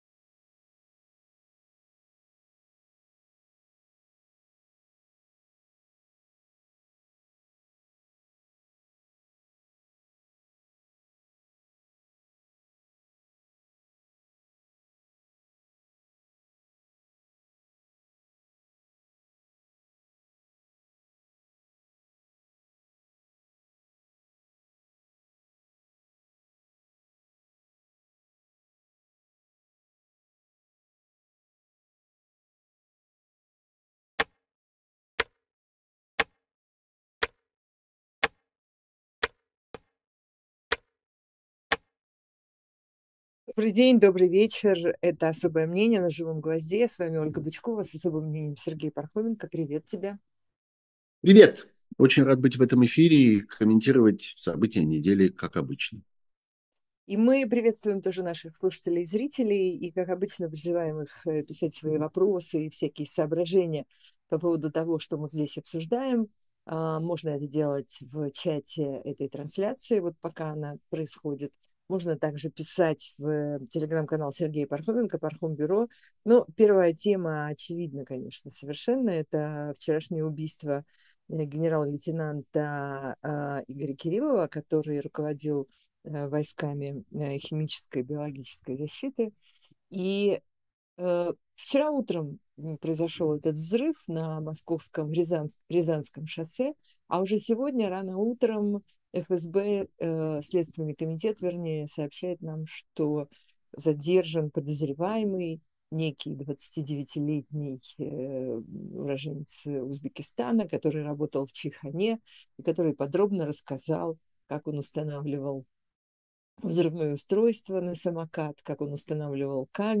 Эфир ведёт Ольга Бычкова